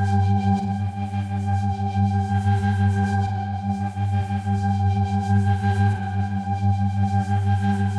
Index of /musicradar/dystopian-drone-samples/Tempo Loops/90bpm
DD_TempoDroneB_90-G.wav